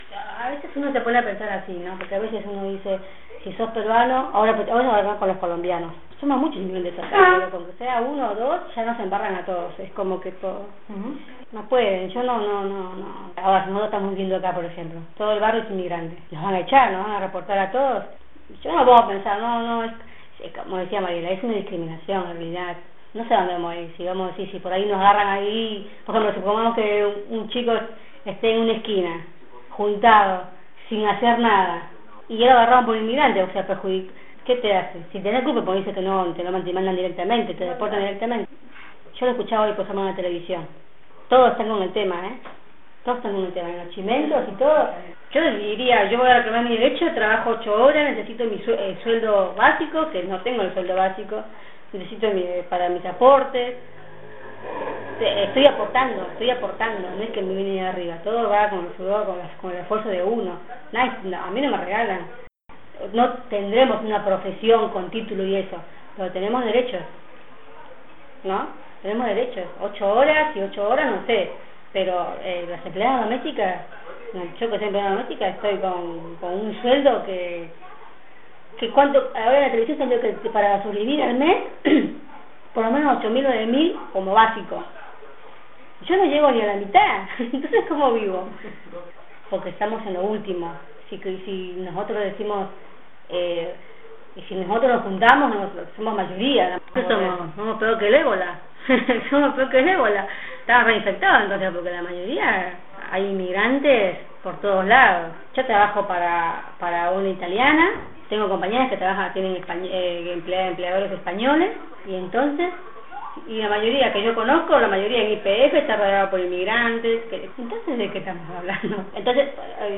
Las voces que podés escuchar más abajo son de mujeres y jóvenes migrantes del Barrio JL Cabezas de La Plata, uno de los más castigados por la inundación del año pasado.